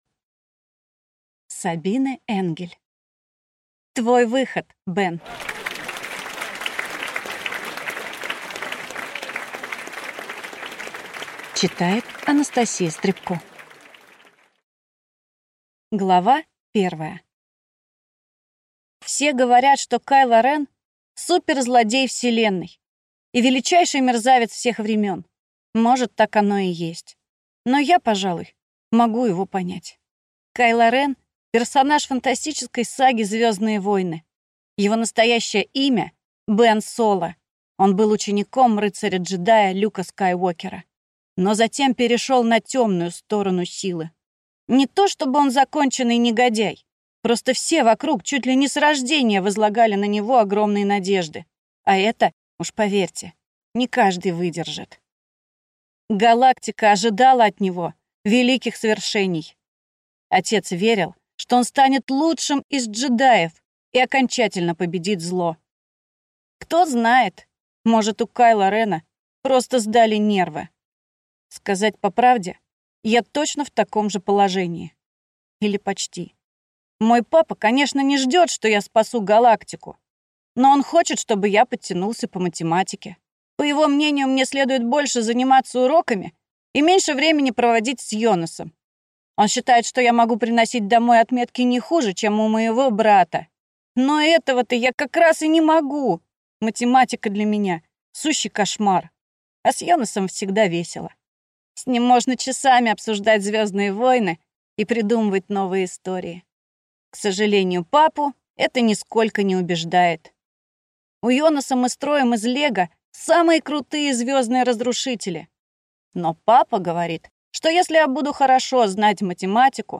Аудиокнига Твой выход, Бен!